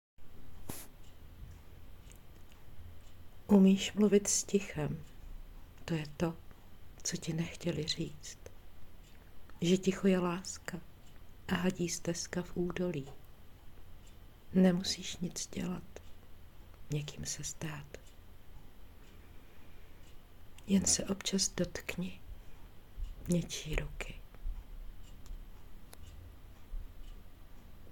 Básně » Ostatní